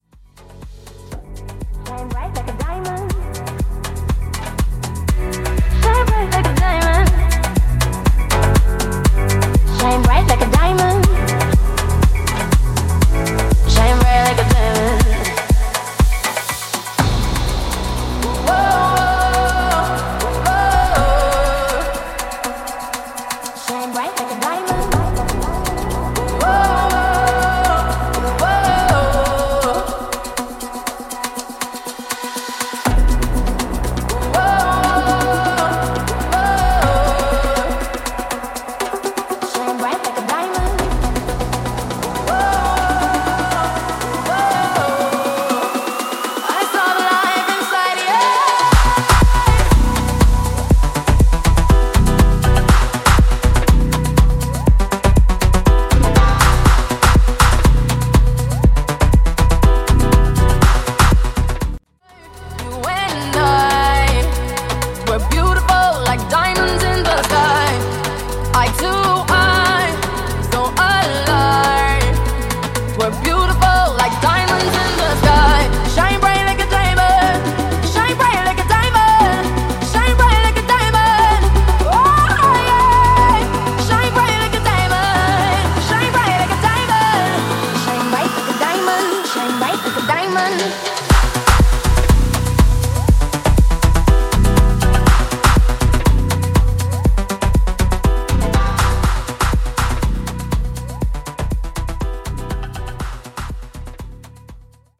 Genre: HIPHOP
Clean BPM: 105 Time